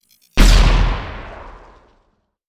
goodcircle/IdleRPG2024 - Assets/_8Sound/Grenade Sound FX/Grenade/Grenade6.wav at cc647107d762824fa3c32648ad9142fc232b2797 - IdleRPG2024 - GoodCircle
Grenade6.wav